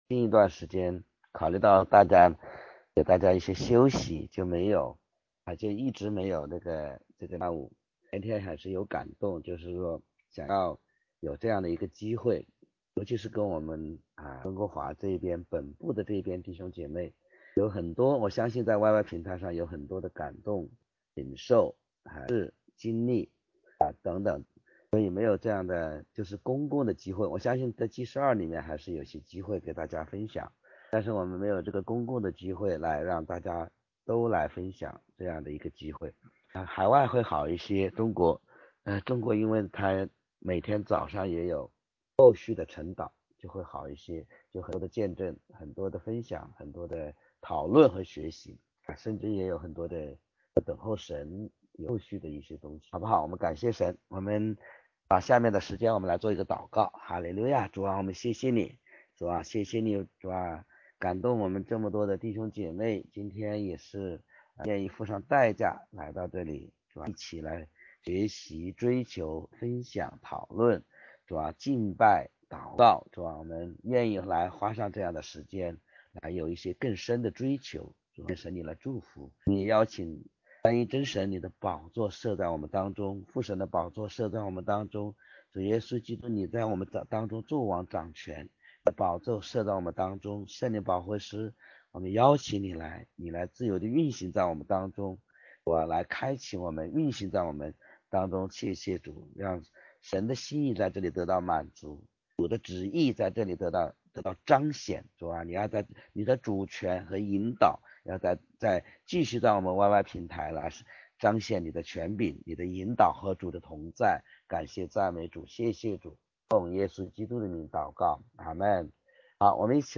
见证分享